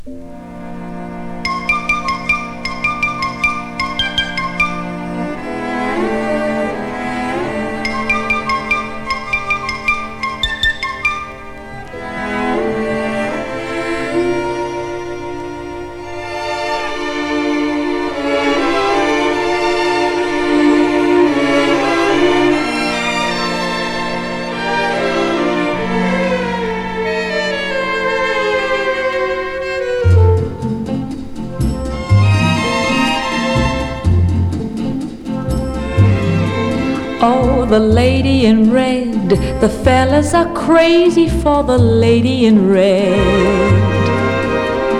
Jazz, Easy Listening, Vocal　USA　12inchレコード　33rpm　Mono